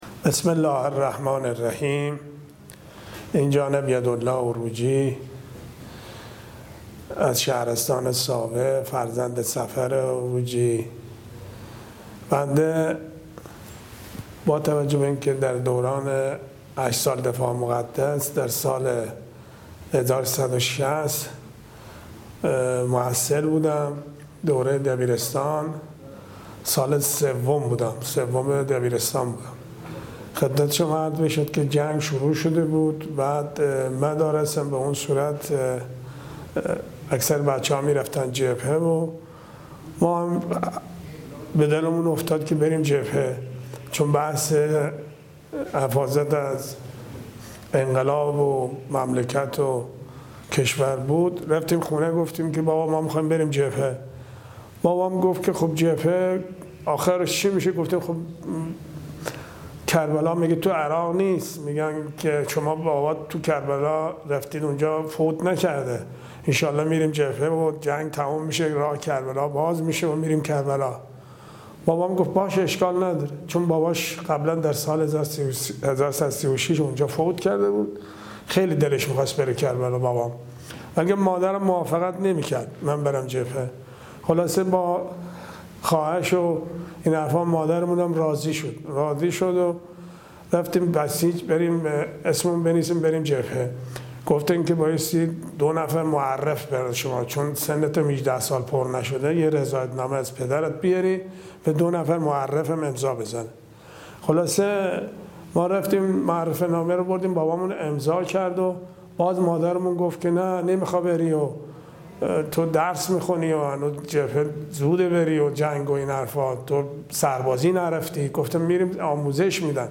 گفت‌وگویی شنیدنی